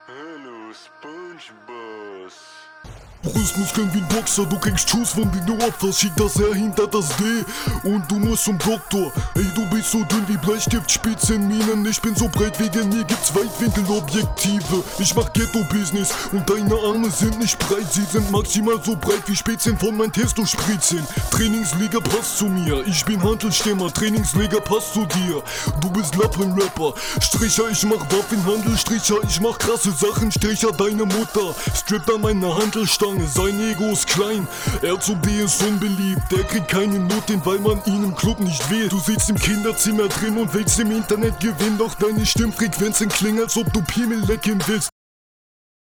Du bist flowlich für die Training ganz ordentlich unterwegs.